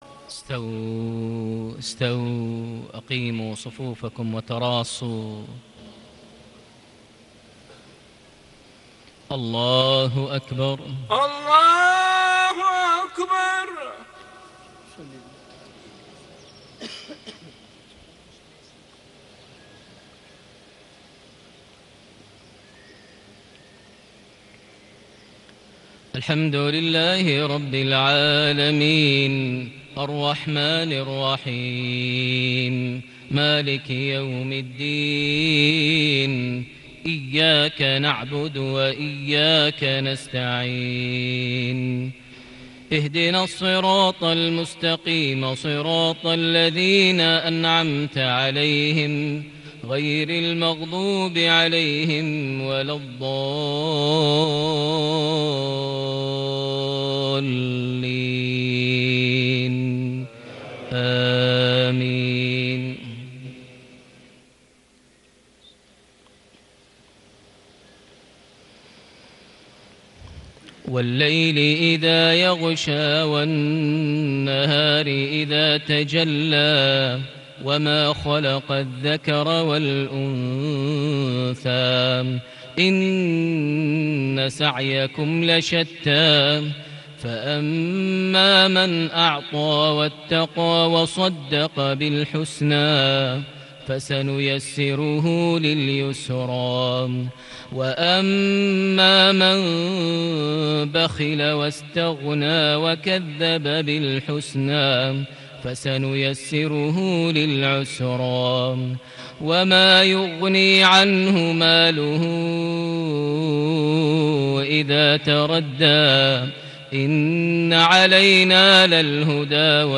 صلاة المغرب ٦ جماد الآخر ١٤٣٨هـ سورتي الليل / القارعة mghrip 5-3-2017 -Surah Al-Lail -Surah Al-Qaria > 1438 🕋 > الفروض - تلاوات الحرمين